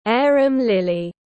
Arum lily /’eərəmˈlɪl.i/